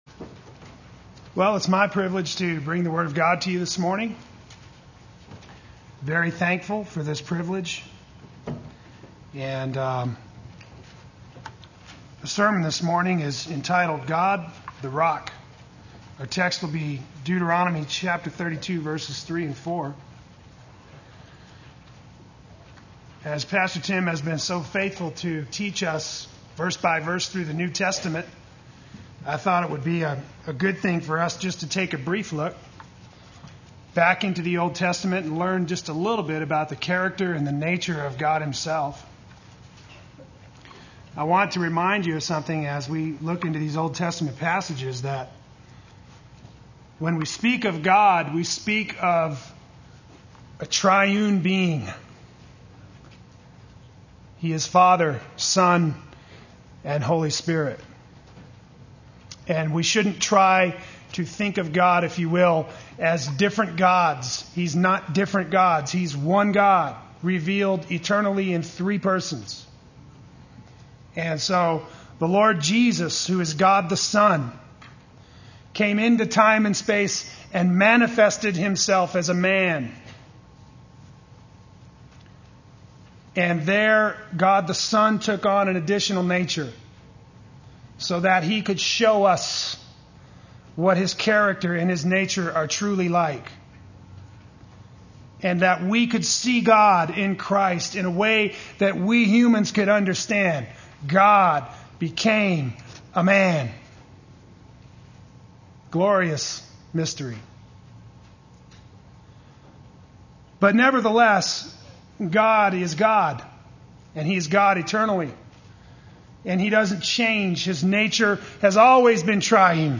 Play Sermon Get HCF Teaching Automatically.
God the Rock Sunday Worship